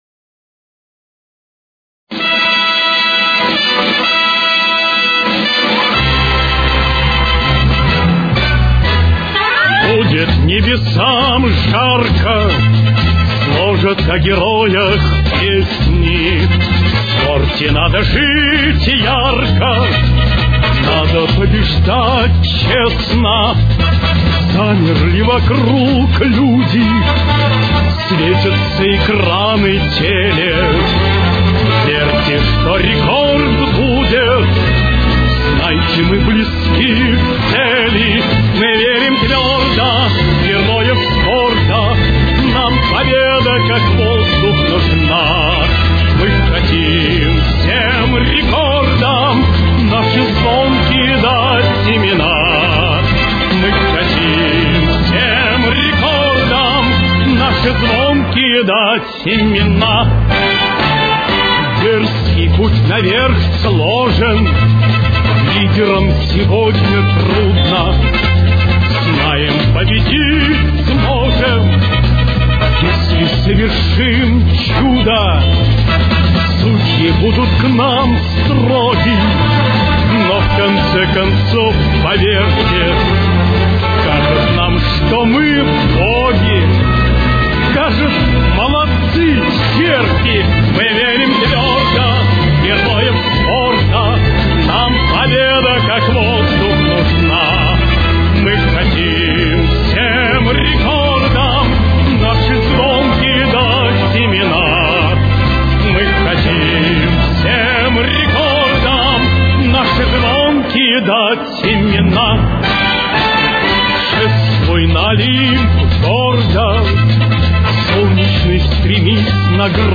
Ля минор. Темп: 130.